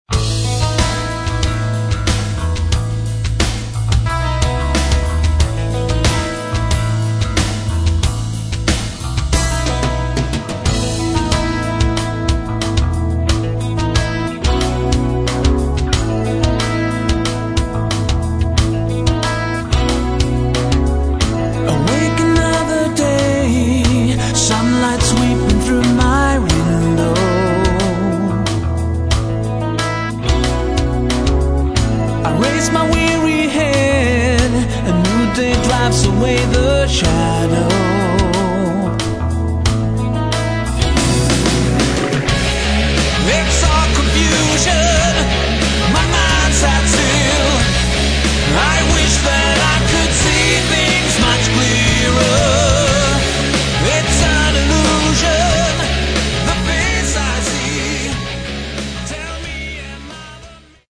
гитара, клавиши, бас
вокал
барабаны